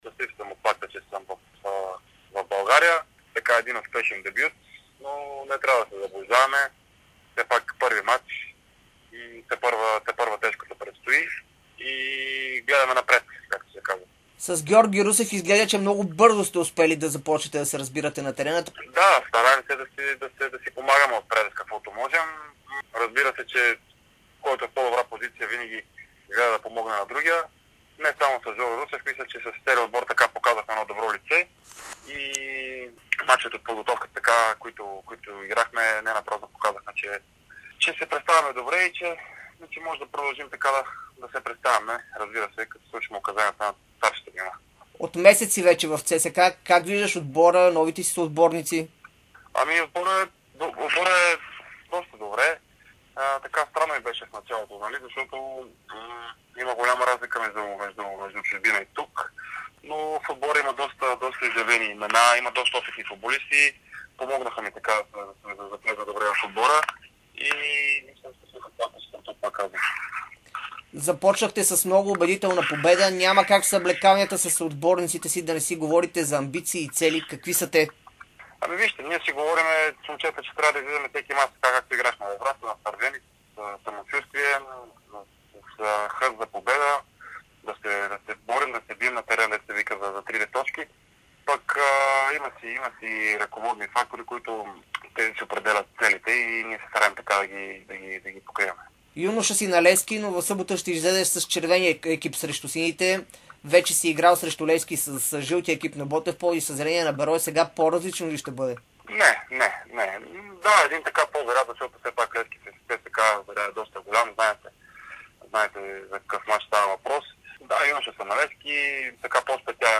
специално интервю за Дарик и dsport